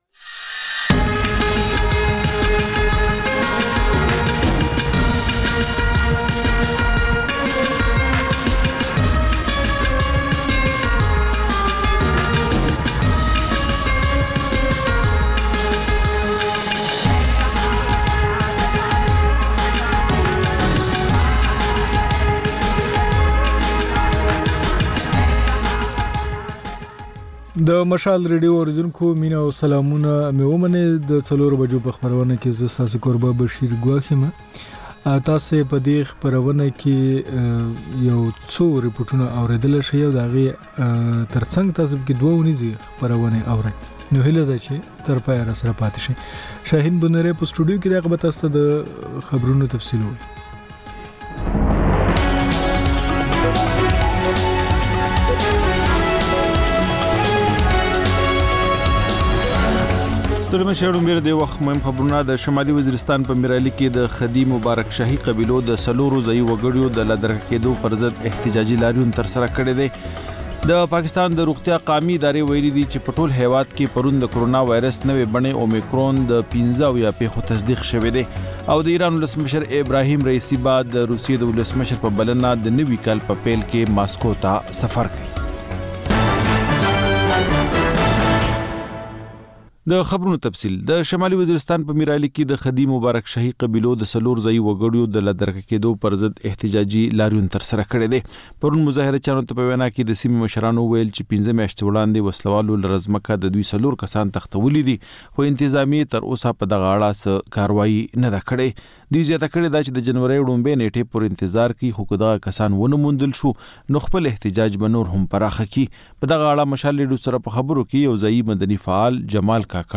د خپرونې پیل له خبرونو کېږي، بیا ورپسې رپورټونه خپرېږي.